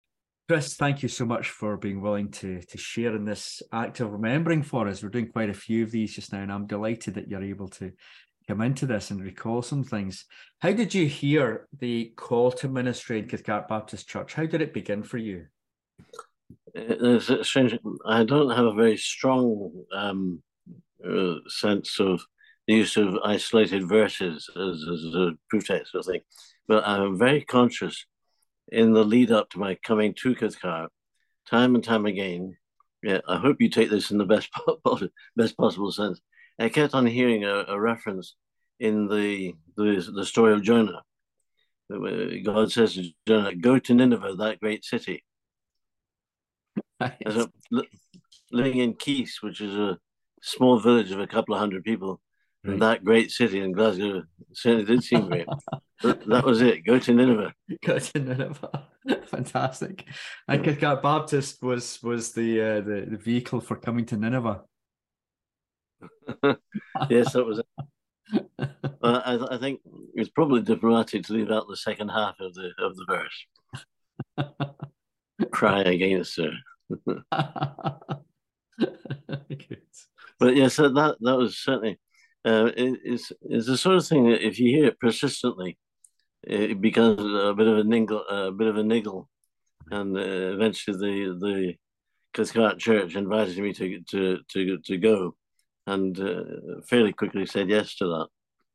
recorded in 2023 over zoom.